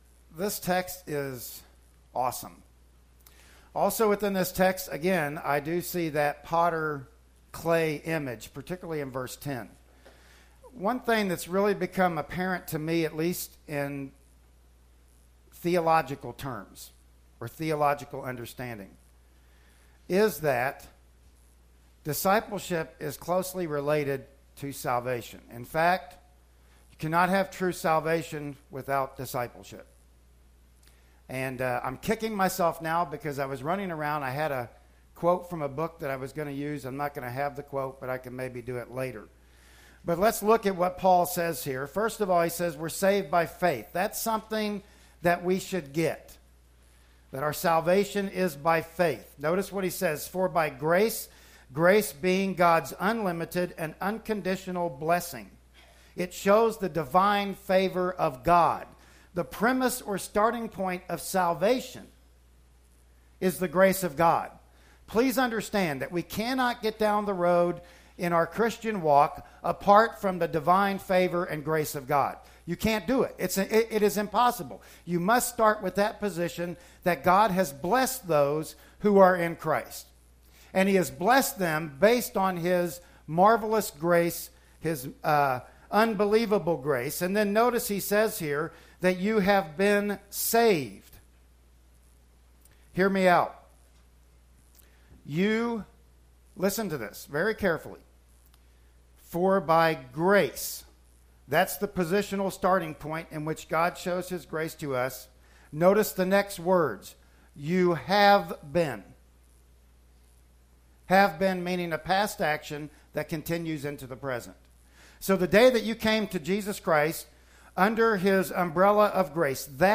"Ephesians 2:8-10" Service Type: Sunday Morning Worship Service Bible Text